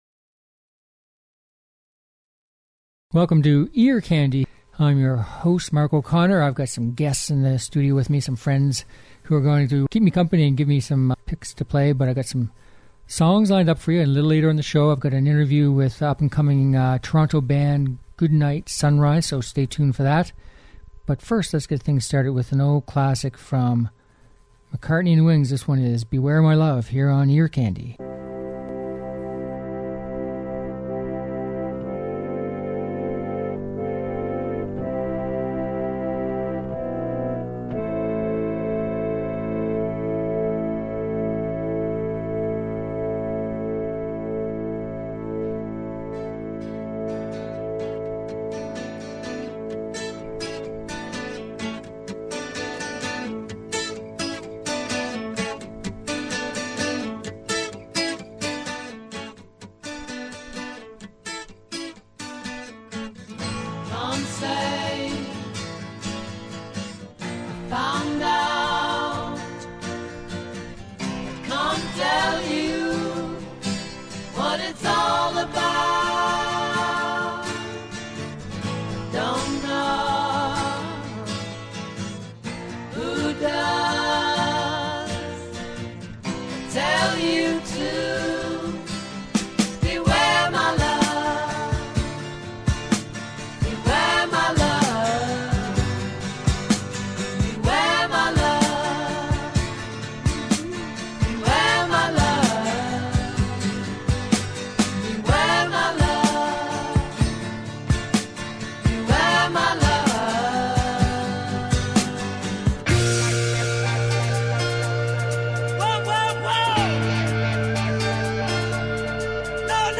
Catchy Uptempo Music From Old and New Artists